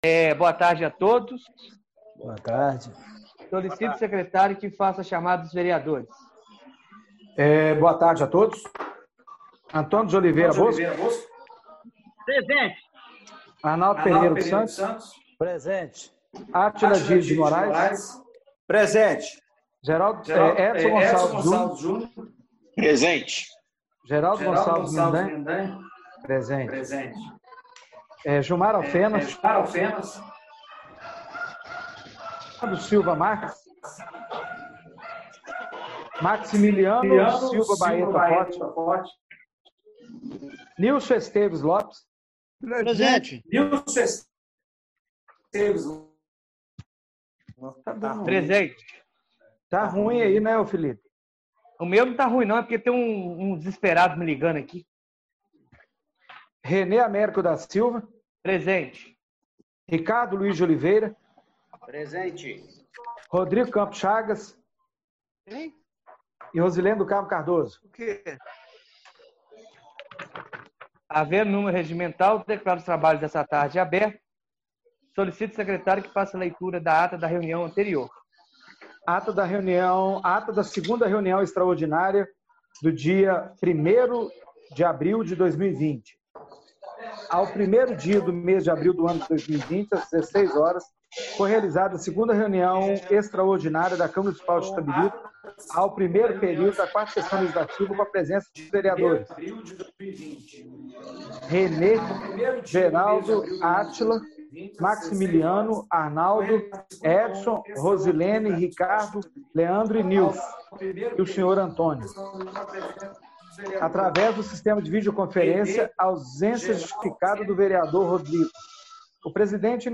Reunião Extraordinária do dia 02/04/2020